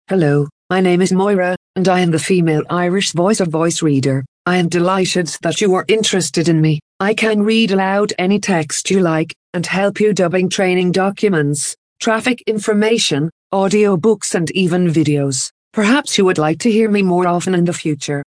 Voice Reader Home 22 English (Irish) - Female voice [Moira]
Voice Reader Home 22 ist die Sprachausgabe, mit verbesserten, verblüffend natürlich klingenden Stimmen für private Anwender.
Schlagworte Aussprachetraining • Englisch-Australisch • Hörbuch • Legasthenie • Sprachausgabe • Sprachsynthese • text to speech • Text to Voice • Vorleseprogramm